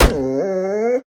Minecraft Version Minecraft Version snapshot Latest Release | Latest Snapshot snapshot / assets / minecraft / sounds / mob / wolf / grumpy / hurt3.ogg Compare With Compare With Latest Release | Latest Snapshot
hurt3.ogg